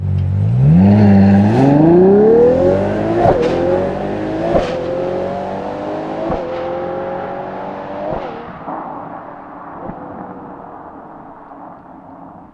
Partenza_da_fermo_1.wav